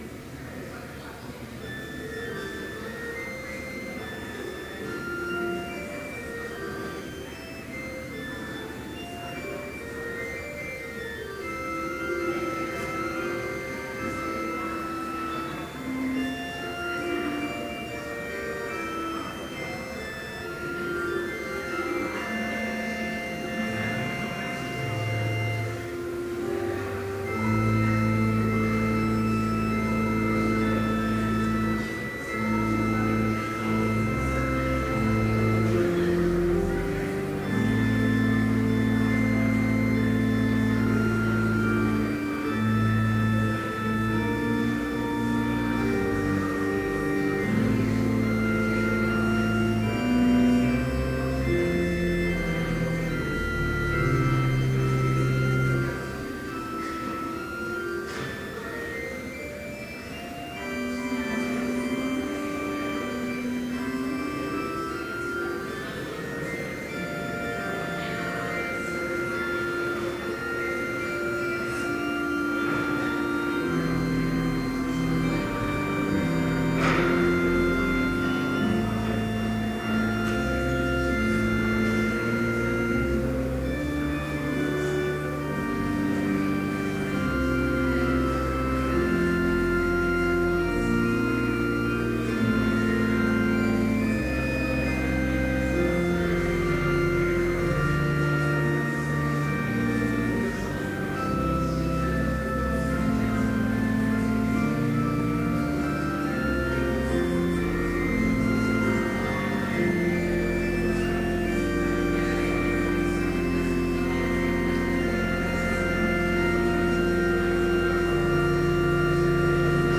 Complete service audio for Chapel - November 21, 2012